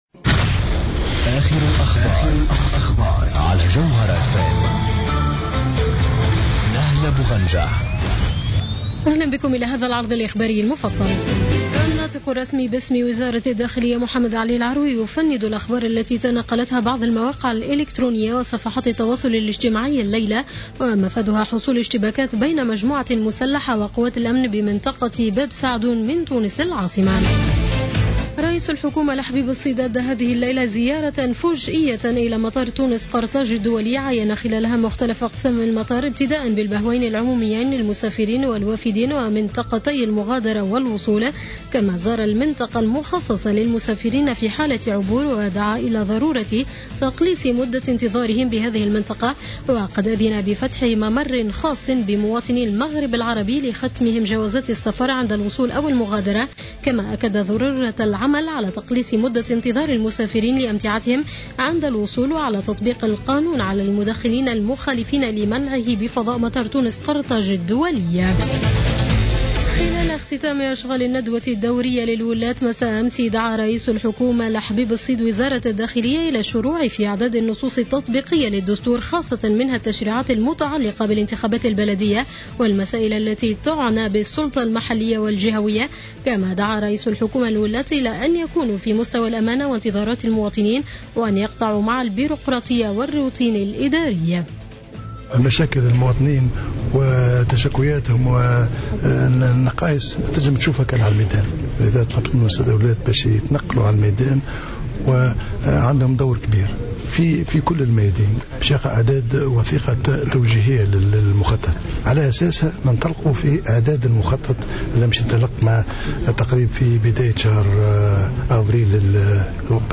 نشرة أخبار منتصف الليل ليوم الأحد 22 فيفري 2015